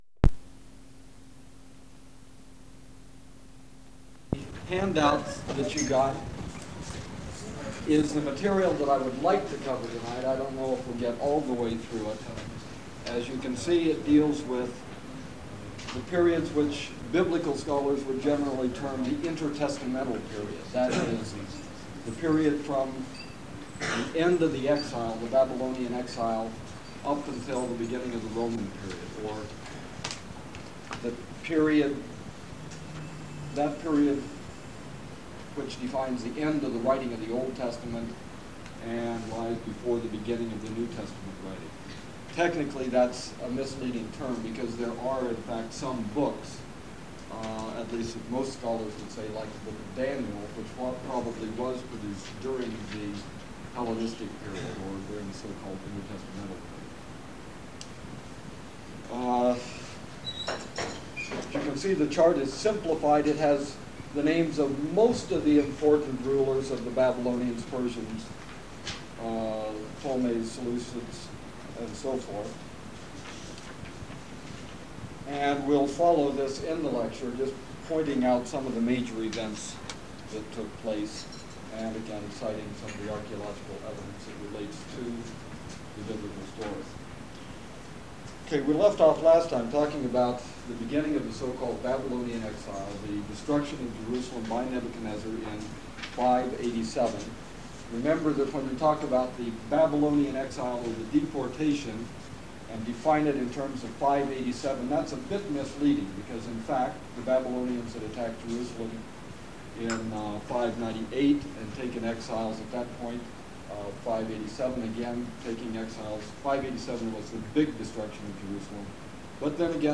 Archaeology of Jordan and Biblical History - Lecture 6
Format en audiocassette ID from Starchive 417974 Tag en Excavations (Archaeology) -- Jordan en Bible -- Antiquities en Archaeology Item sets ACOR Audio-visual Collection Media Arch_Bible_06_access.mp3